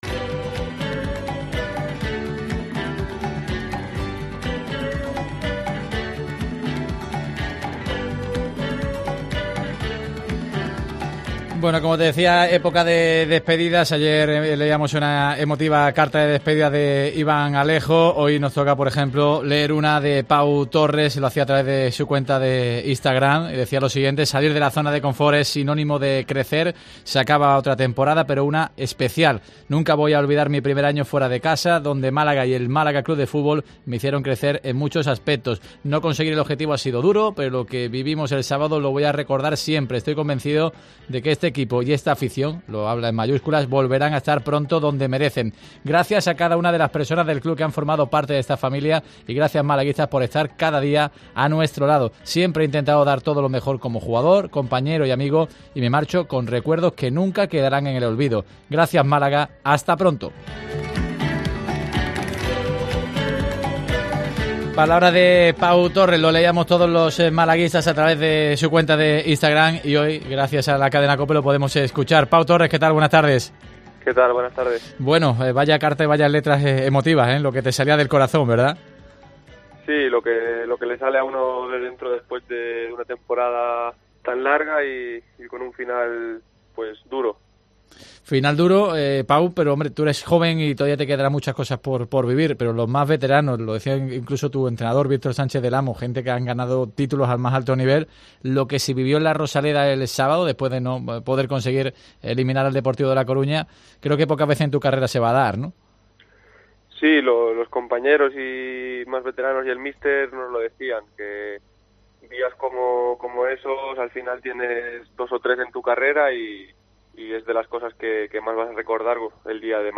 El jugadador del Malaga se despidió en los micrófonos de COPE Málaga de los aficionados del Málaga.